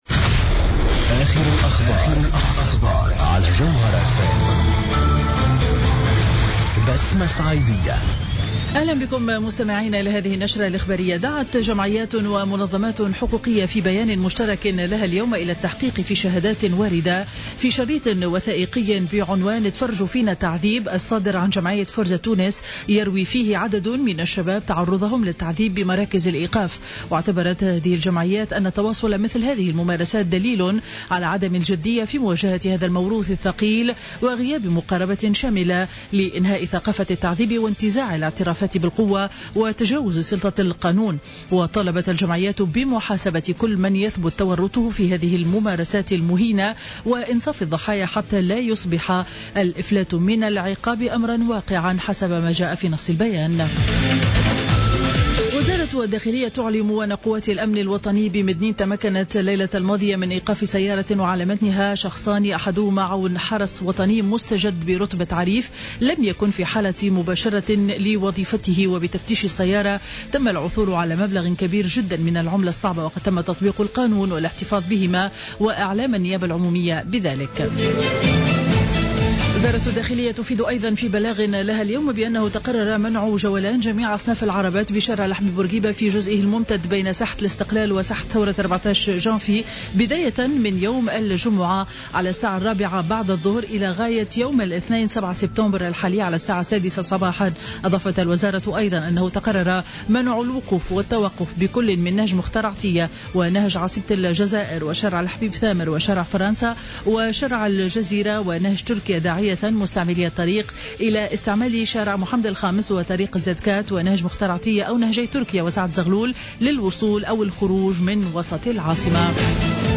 نشرة أخبار منتصف النهار ليوم الخميس 3 سبتمبر 2015